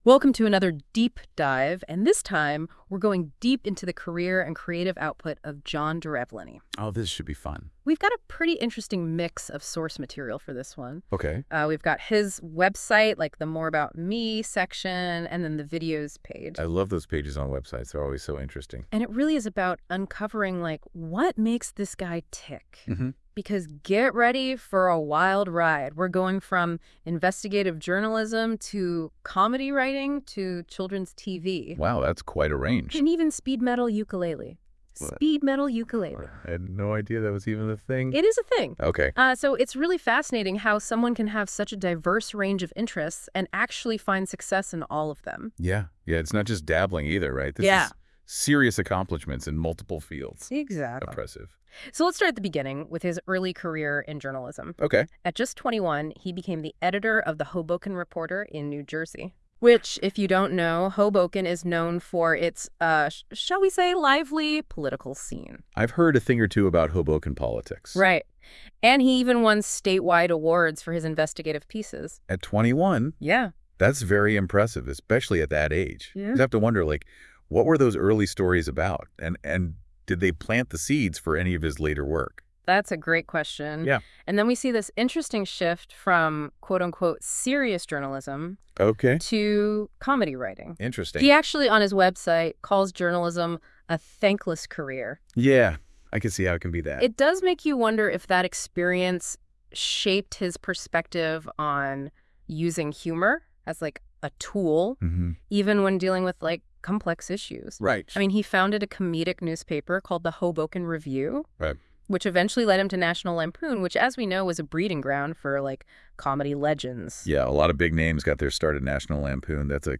DEEP DIVE – a completely AI-generated podcast